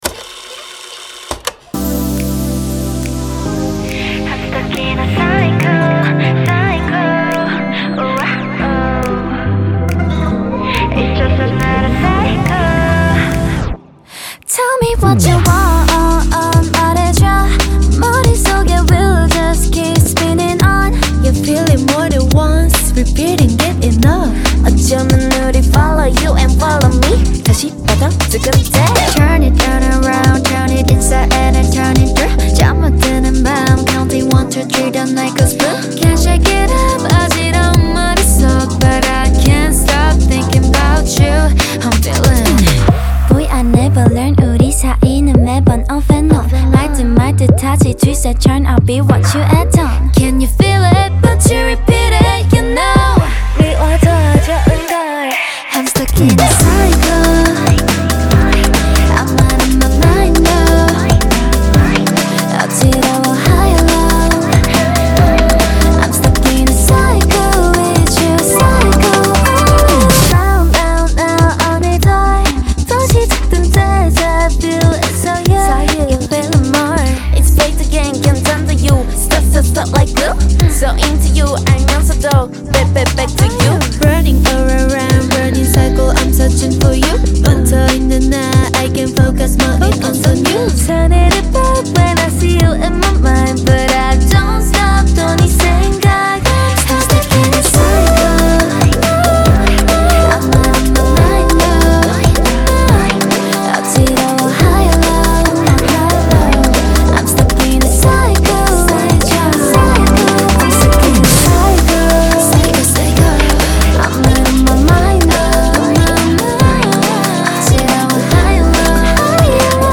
آهنگ جدید کره ای